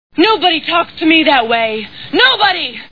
Space Balls Movie Sound Bites